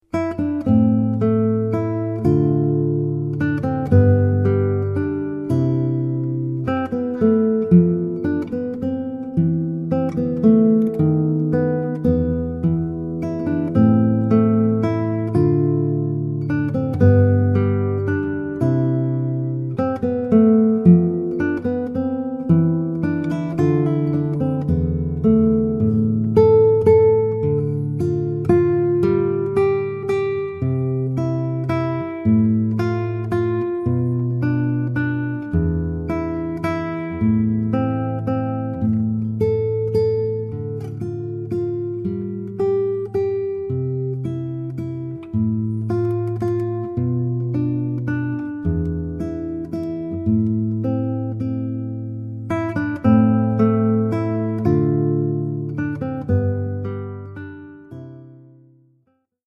Oeuvre pour guitare solo.